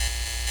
LightFlickerLoop.wav